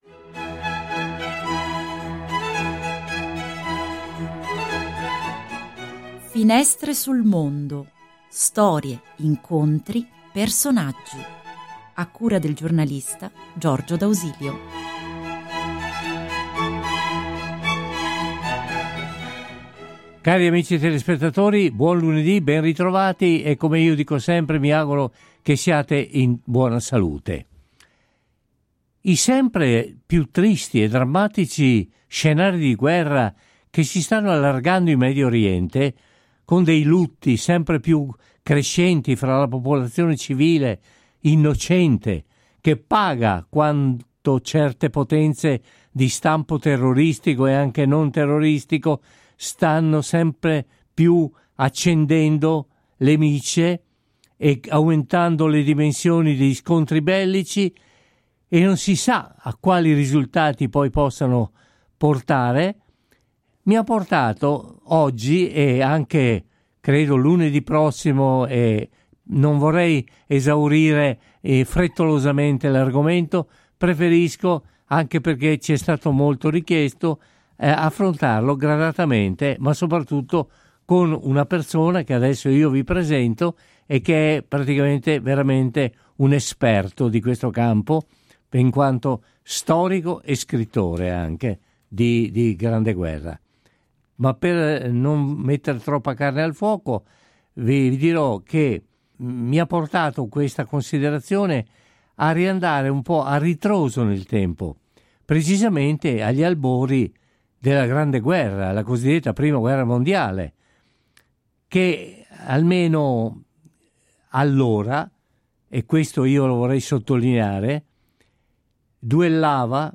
Intervista al programma Finestre sul Mondo del 28 ottobre 2024